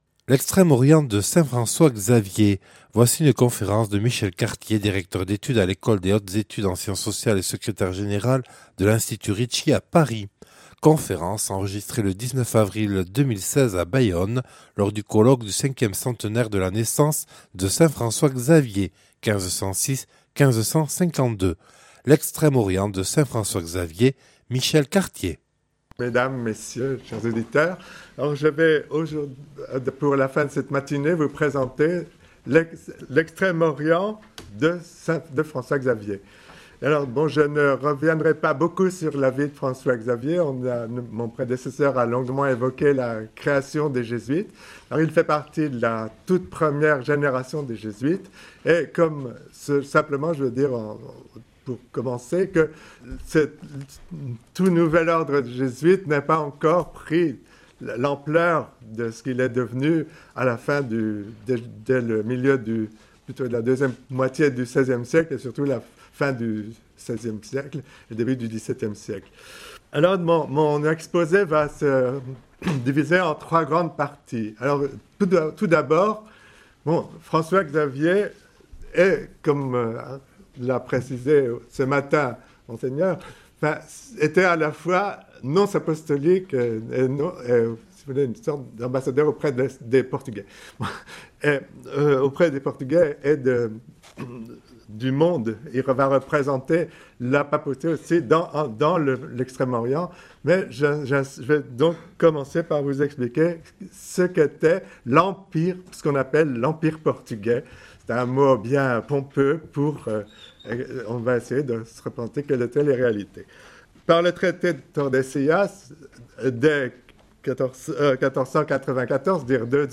Enregistrée le 19/04/2006 à Bayonne lors du colloque du 5ème centenaire de la naissance de saint François-Xavier (1506-1552)